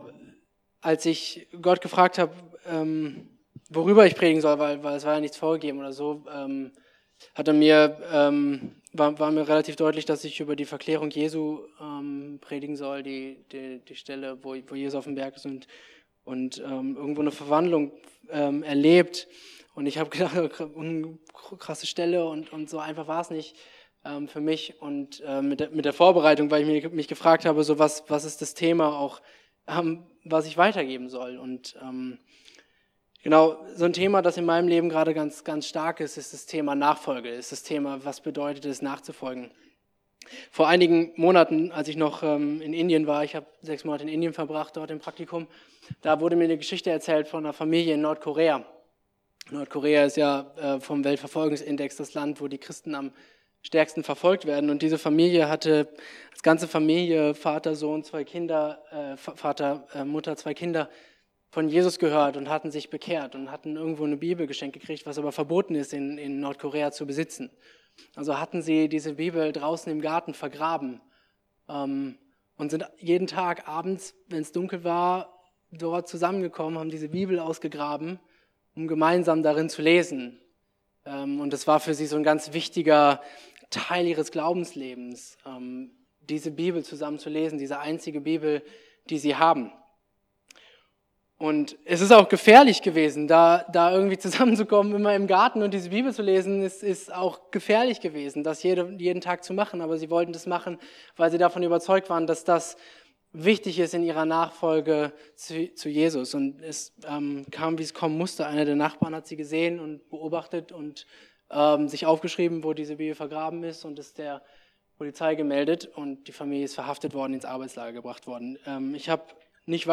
In seiner Predigt vom 30.05.2015